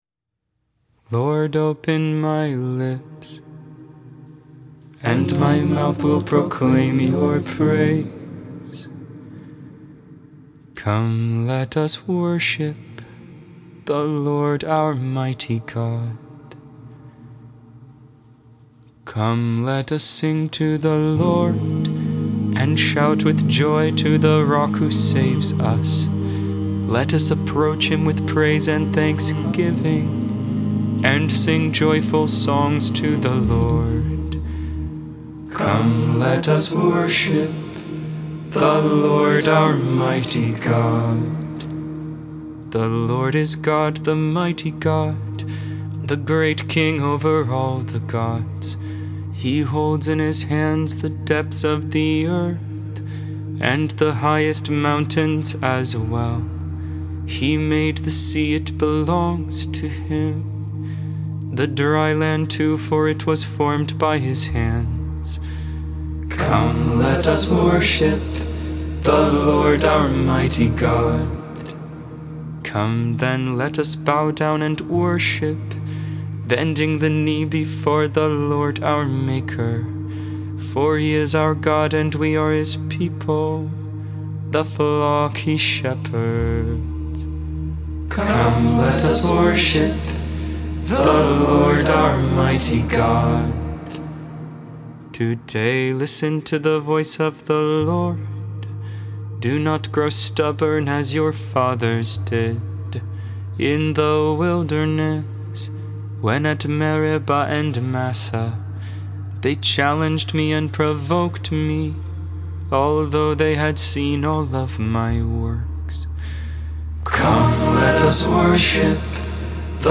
Lauds, Morning Prayer for the 26th Tuesday in Ordinary Time, September 27th, 2022.